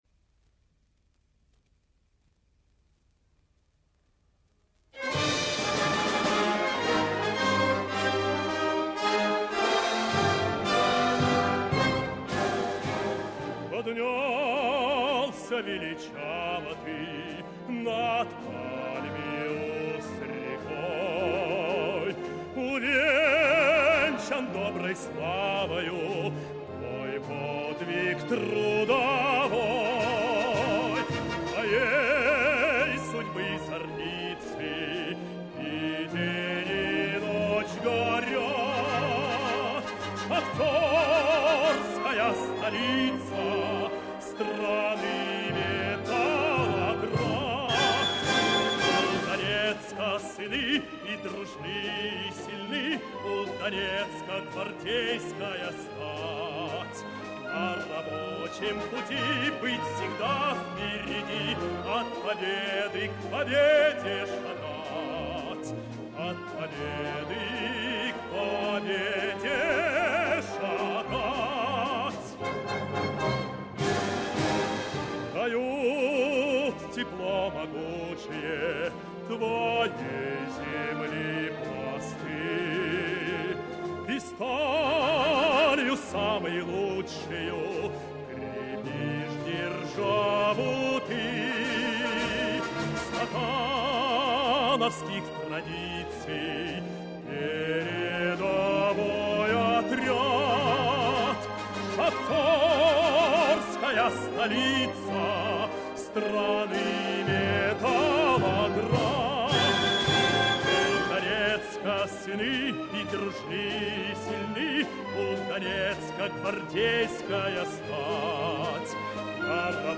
Солисты: